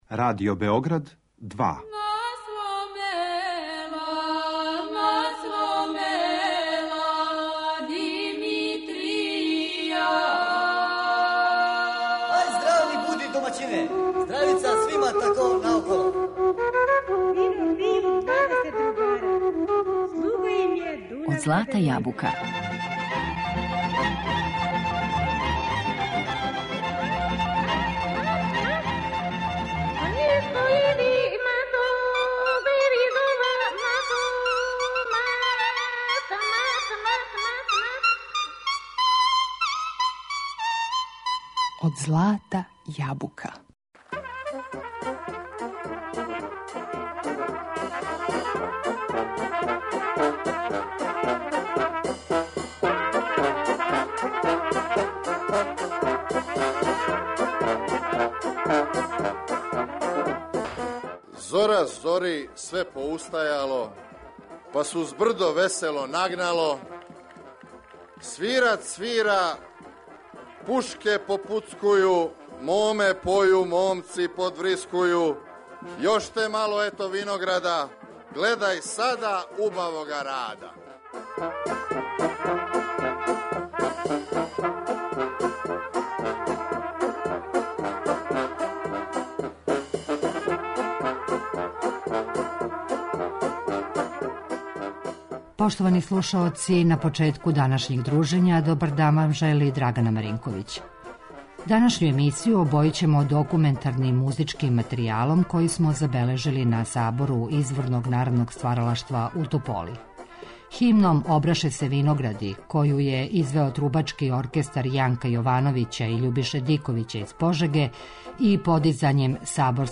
Данашњу емисију обојићемо документарним музичким материјалом који смо забележили на 53. Сабору изворног народног стваралаштва у Тополи.